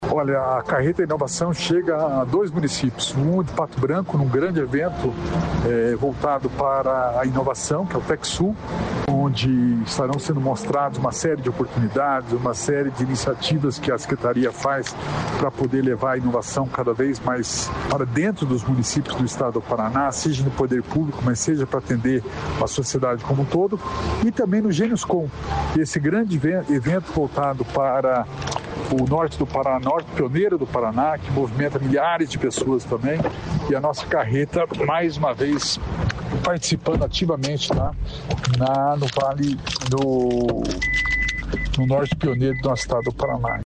Sonora do secretário da Inovação e Inteligência Artificial, Alex Canziani, sobre a carreta da Inovação